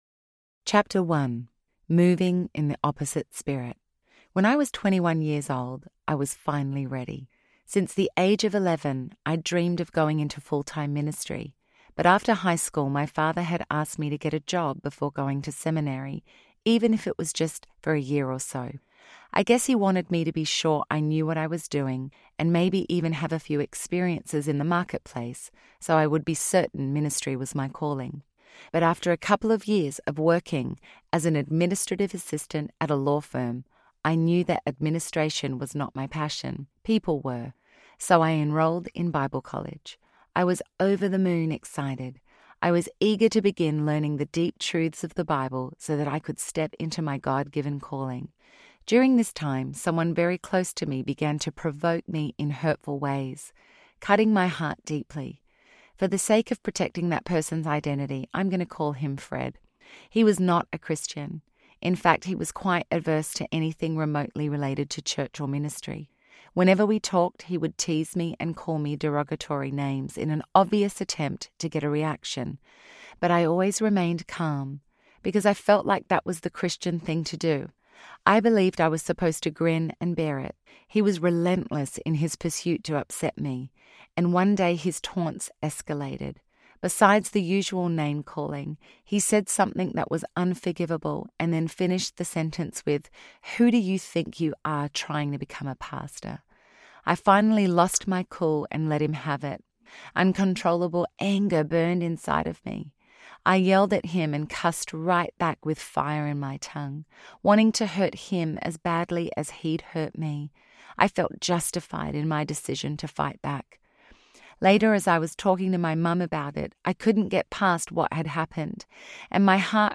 The Opposite Life Audiobook